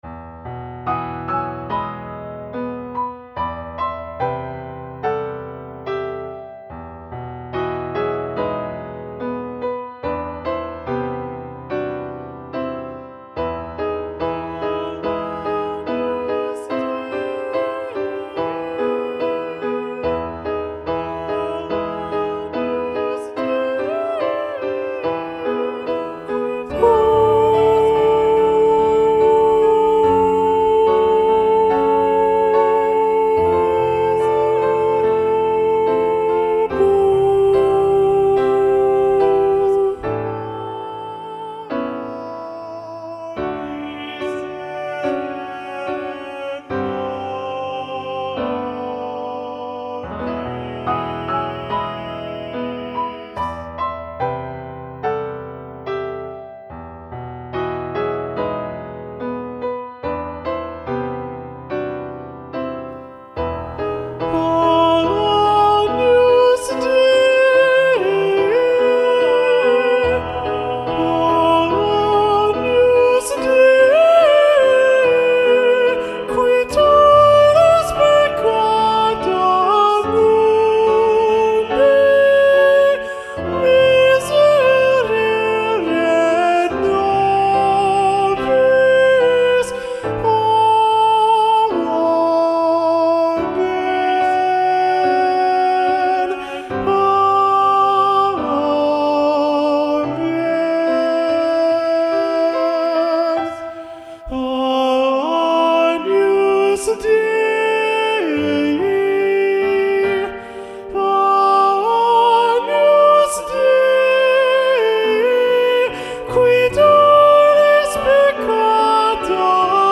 Lo ULTIMO sopranos
Agnus-Dei-Missa-Festiva-SATB-Soprano-Predominant-John-Leavitt.mp3